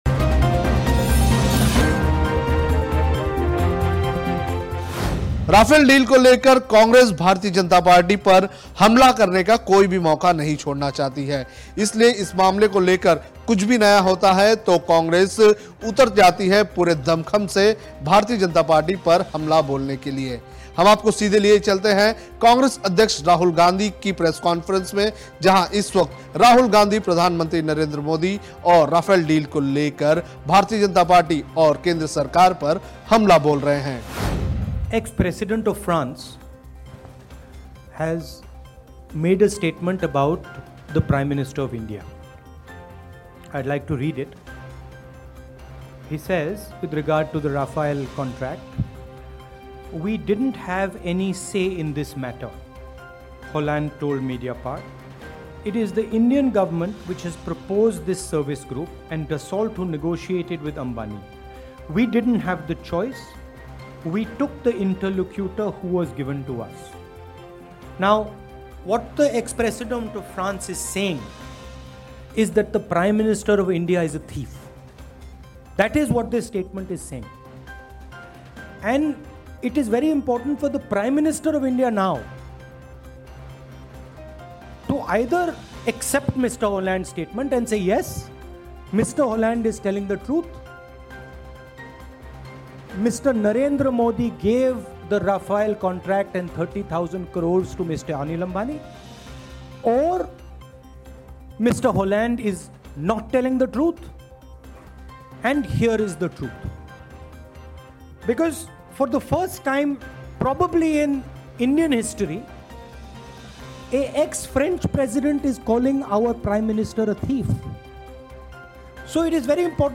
न्यूज़ रिपोर्ट - News Report Hindi / राफेल डील को लेकर राहुल गाँधी ने फिर नरेंद्र मोदी को कहा "चोर" I Rahul Gandhi, Rafale Deal